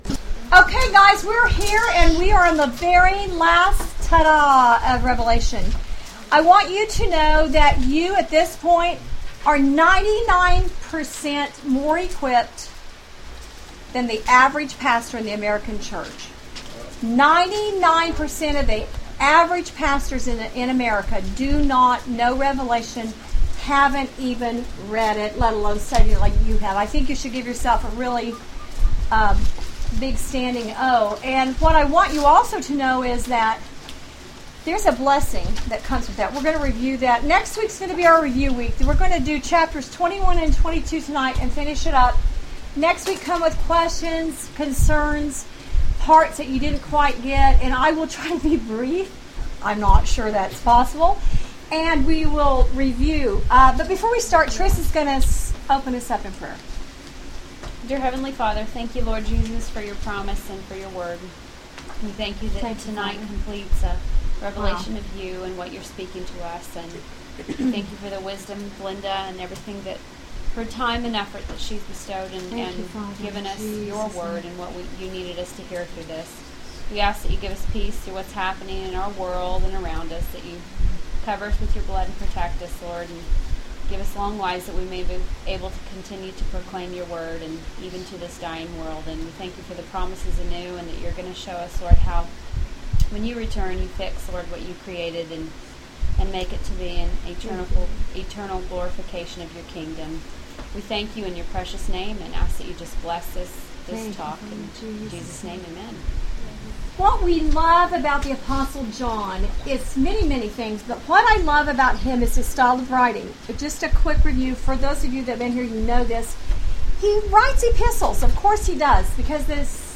Bible Study Audio